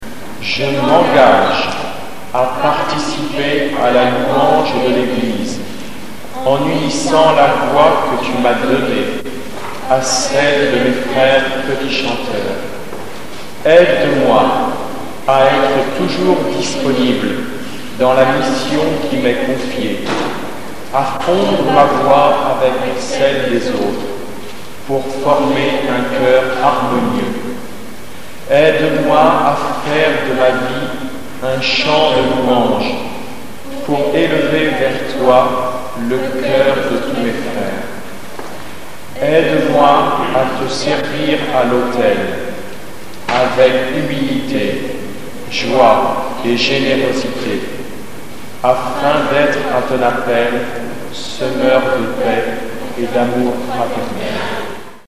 CHOEUR D'ENFANTS